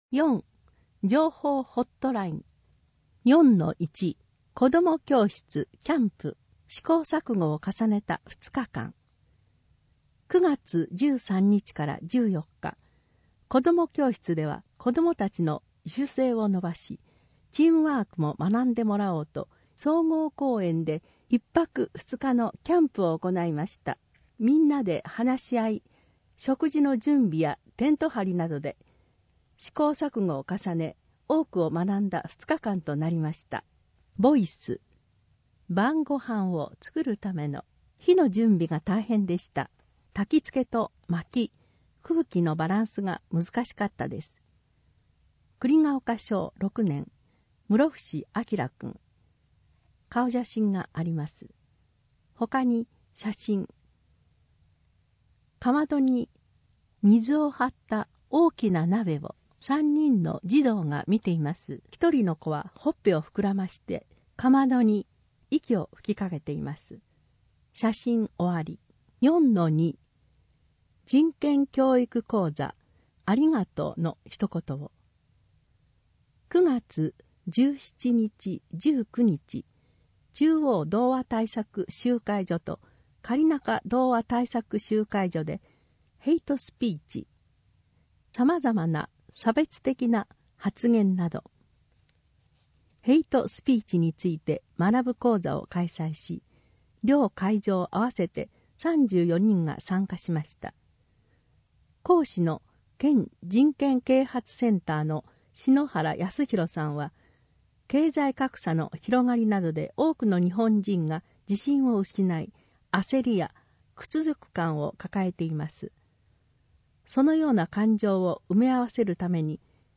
毎月発行している小布施町の広報紙「町報おぶせ」の記事を、音声でお伝えする（音訳）サービスを行っています。音訳は、ボランティアグループ そよ風の会の皆さんです。